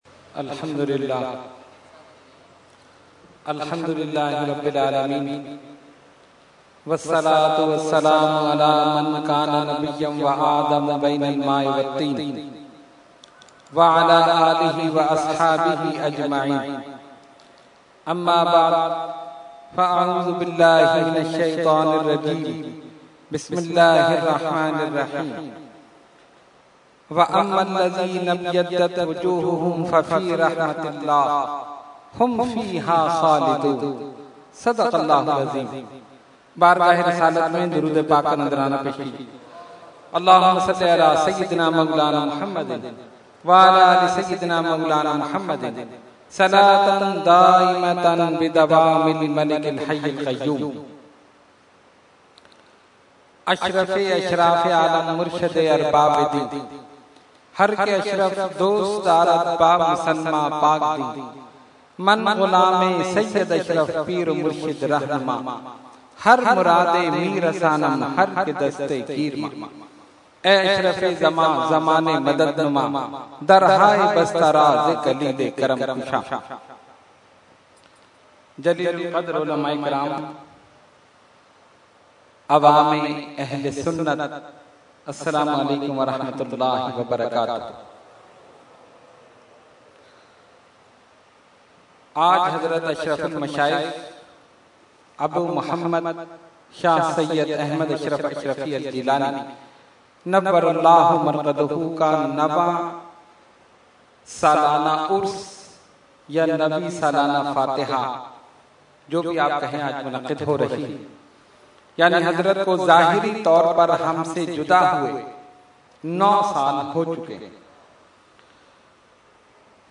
Category : Speech | Language : UrduEvent : Urs Ashraful Mashaikh 2014